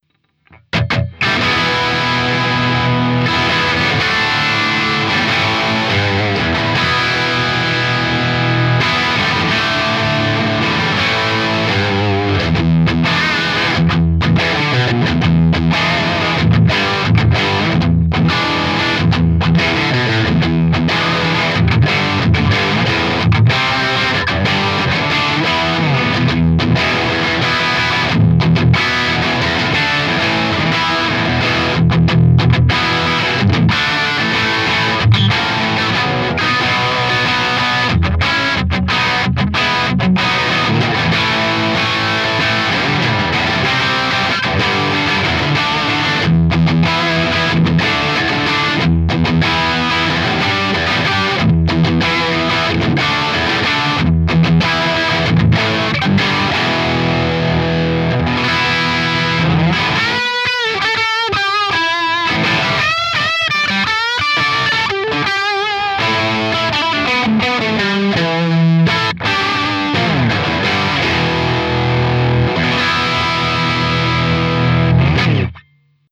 Mein Ceriatone-Plexi, im Herzen ein regulärer "Hot-Rod"-Plexi mit Optionen, lässt sich sowohl im regulären Modus (3 Gainstufen, 2x Bright Switch) als auch im "Oldschool-Modus" (2 Gainstufen, 1x Bright Switch) betreiben, wobei ersterer in Richtung JCM800+ gehen soll und letzterer in Richtung alter Plexi. Ich habe also kurzerhand "nach gusto" zwei Soundfiles eingespielt: Eins mit normaler Vorstufenzerre wie man sie kennt, den Amp mittelmäßig stark aufgedreht, sodass die Endstufe auf jeden Fall zu arbeiten hat, aber die Zerre ausschließlich aus der Vorstufe geholt wird.